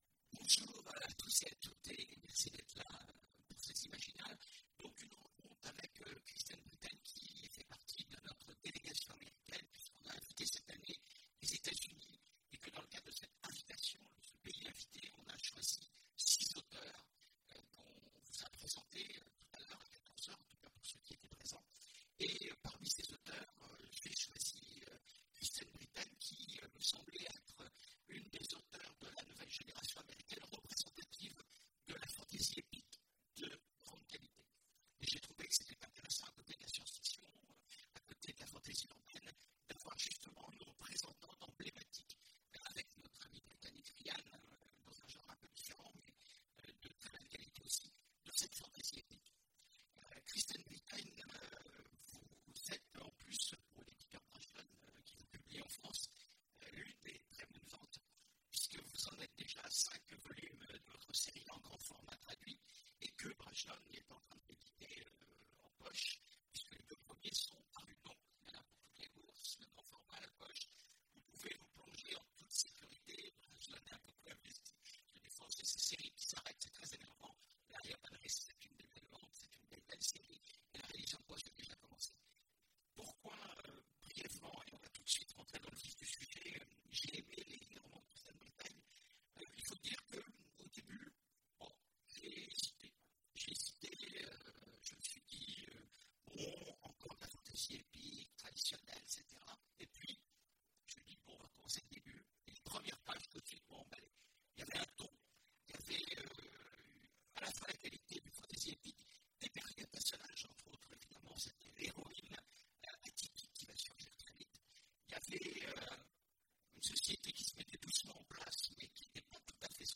Imaginales 2016 : Entretien avec… Kristen Britain
Kristen Britain Télécharger le MP3 à lire aussi Kristen Britain Genres / Mots-clés Rencontre avec un auteur Conférence Partager cet article